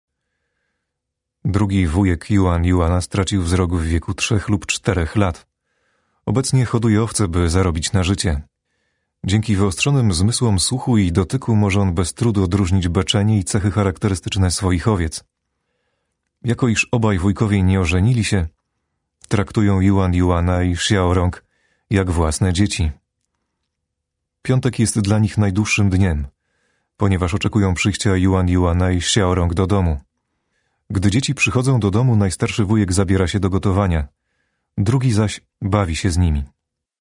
Male 30-50 lat
Warm voice timbre and interesting tone refined through work as a radio presenter and journalist. Distinctive articulation and impeccable diction.
Narracja lektorska